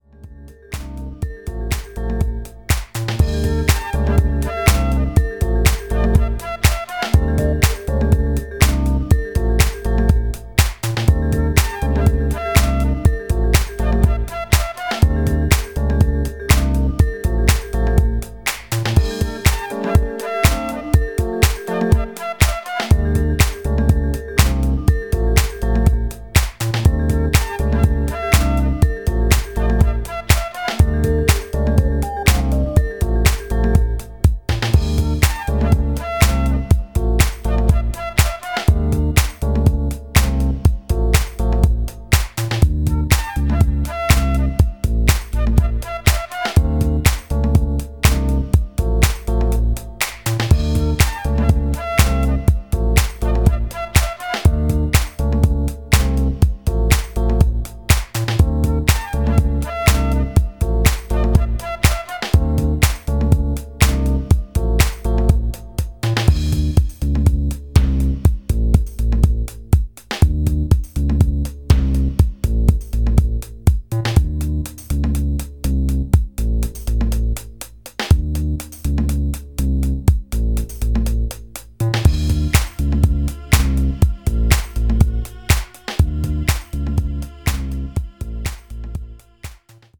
ラフでミニマルなディスコサンプルと楽観的で時に調子外れのメロディー、もっさりと足取りを奪うグルーヴ。
何はともあれディスコ/ハウス史に残る、キュートで親しみやすくクレイジーな金字塔です。